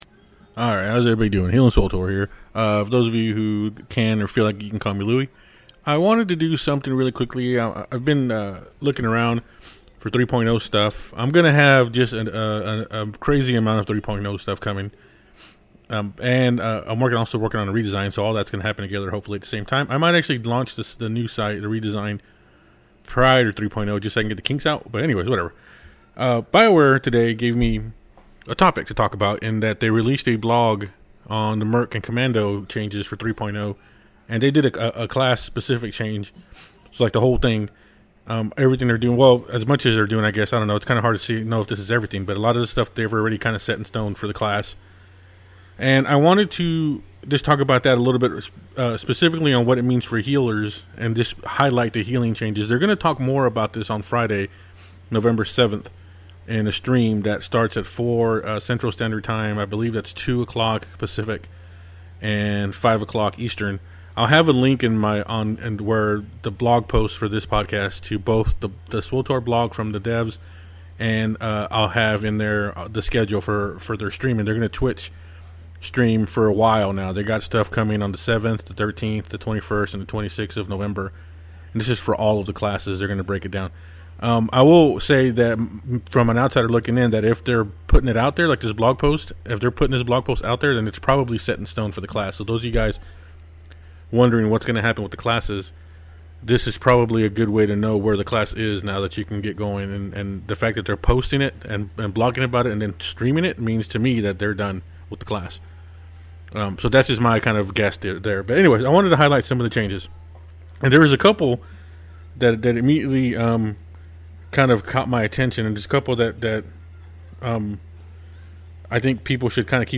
You can listen to me being cutoff at the very end.